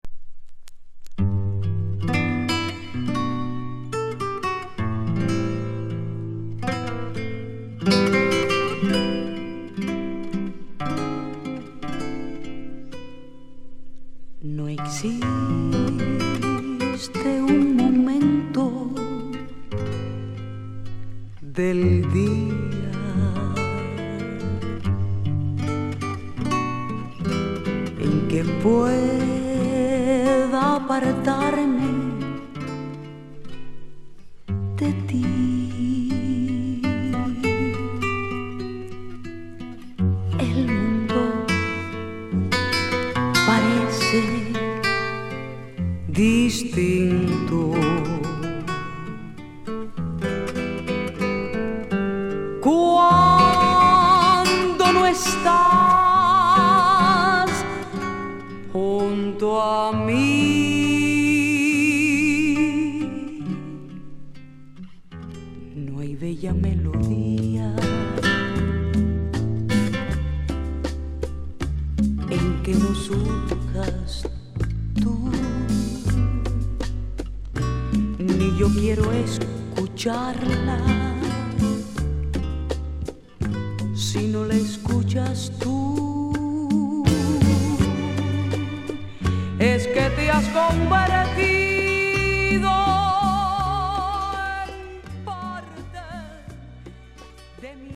1970年から活動を始めたキューバの女性シンガー
往年のボレロ/フィーリンの名曲カバーも収録